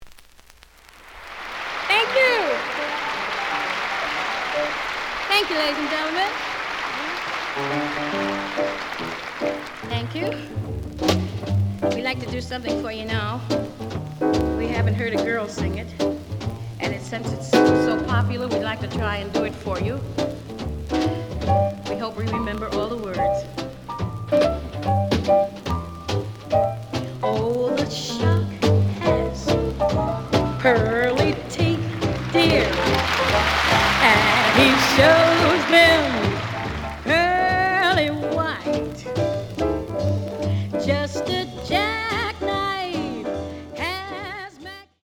The audio sample is recorded from the actual item.
●Genre: Vocal Jazz
Looks good, but slight noise on both sides.